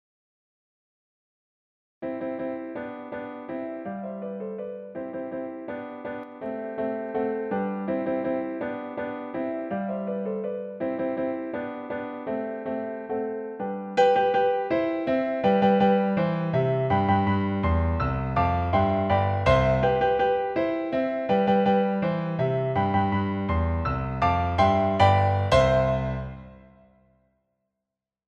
Kafka page 199 - Marche pour piano.
Brève et très sérieuse marche pour piano ou clavecin.
Kafka_Pagina199_MarciaPerPianoforte.mp3